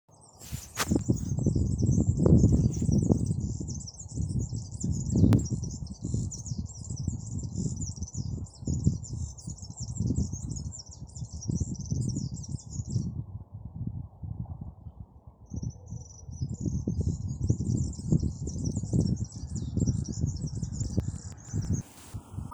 Ģirlicis, Serinus serinus
Administratīvā teritorijaRīga
StatussDzied ligzdošanai piemērotā biotopā (D)